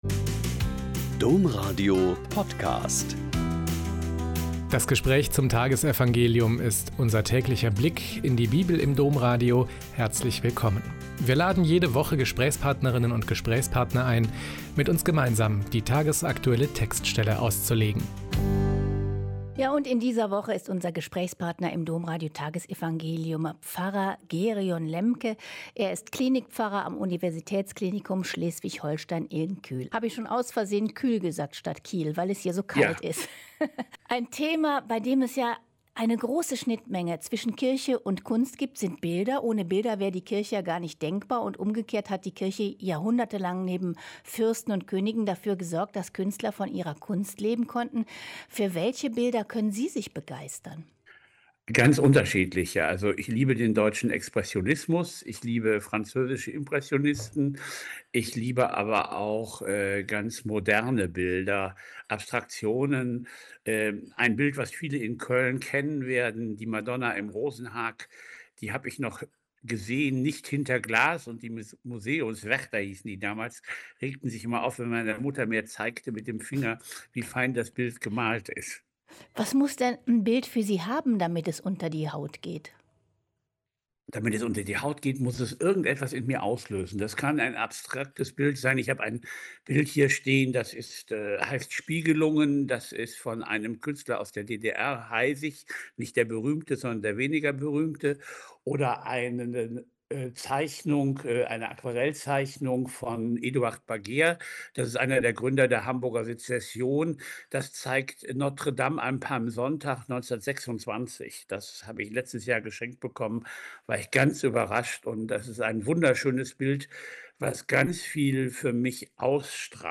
Joh 13,16-20 - Gespräch